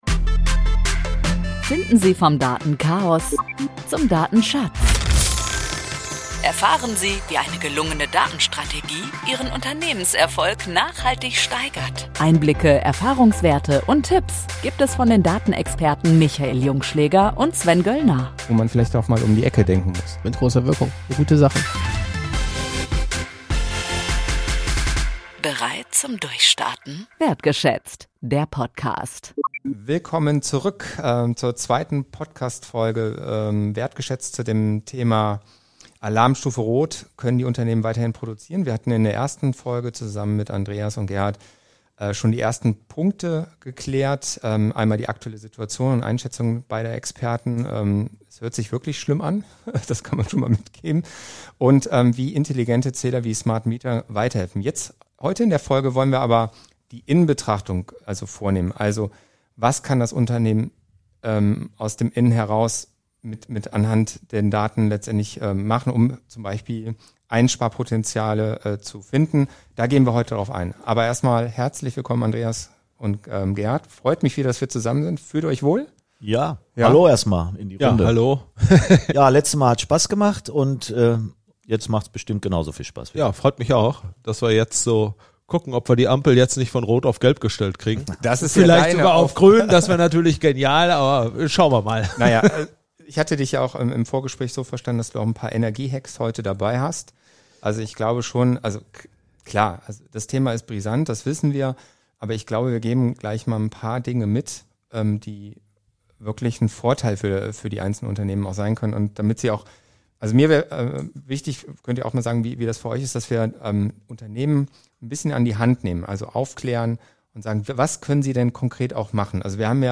Wir erklären in einer Doppelfolge unter anderem das Thema Smart Metering und sprechen dieses Mal zu dritt